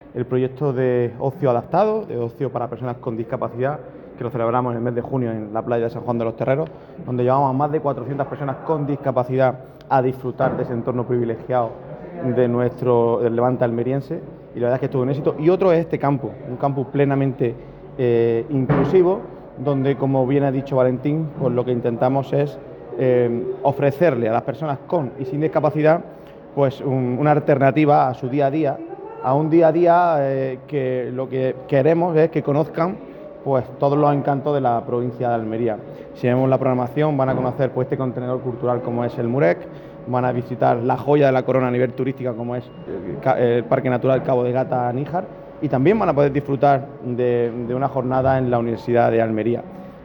06-11_faam_diputado.mp3